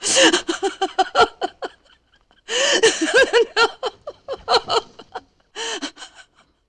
sob.wav